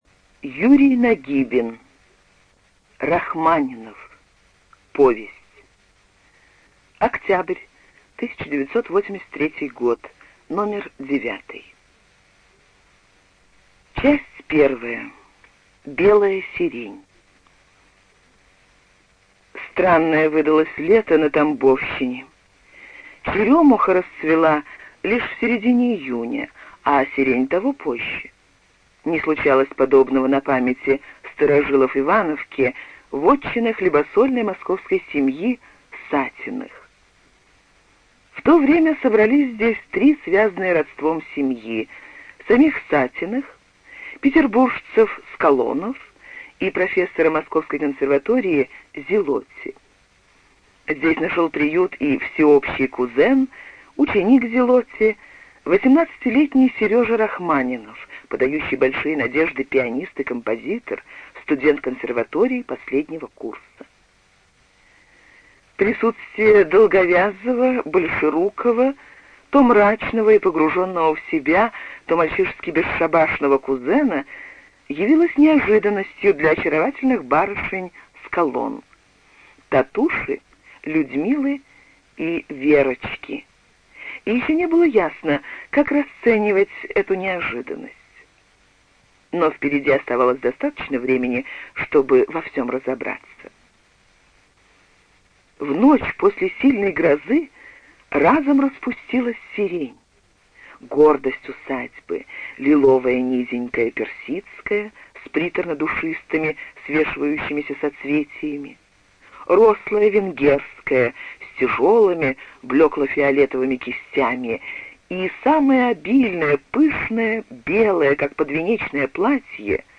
ЖанрСоветская проза
Студия звукозаписиЛогосвос